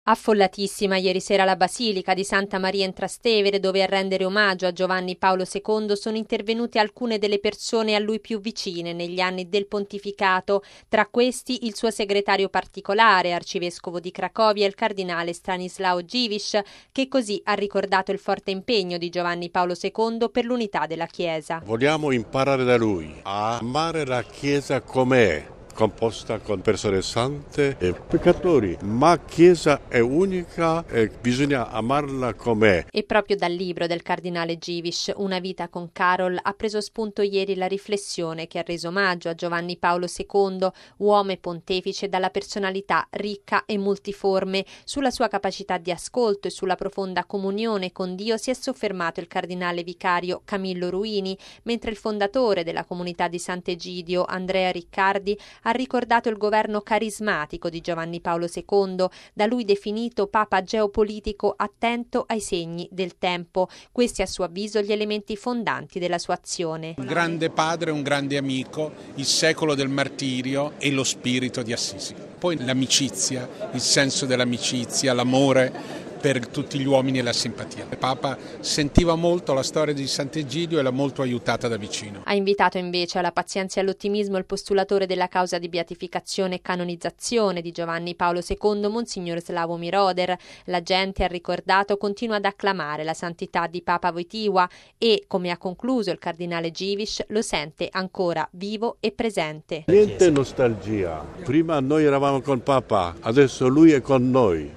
Affollatissima ieri sera la Basilica di Santa Maria in Trastevere, dove ieri hanno reso omaggio a Giovanni Paolo II, nel III anniversario della sua scomparsa, alcune delle persone a lui più vicine negli anni del pontificato.